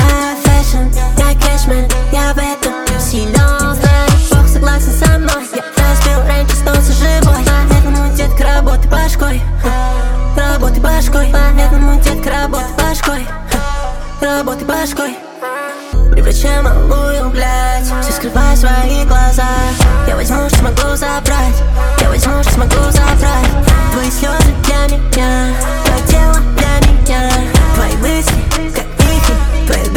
Hip-Hop Hip-Hop Rap
Жанр: Хип-Хоп / Рэп